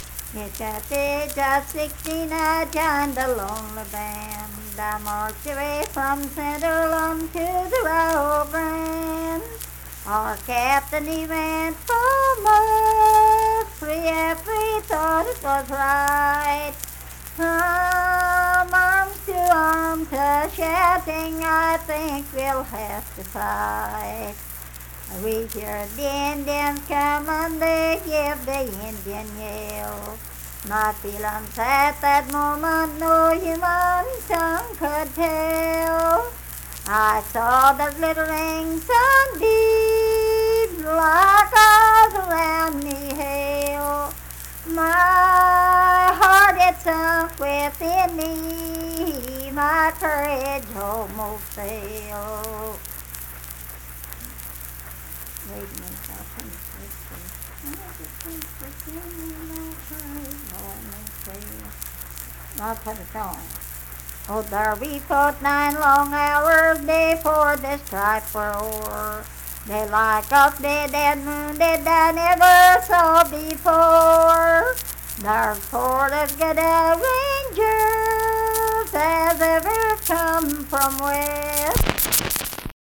Unaccompanied vocal music performance
Verse-refrain 3(8).
Voice (sung)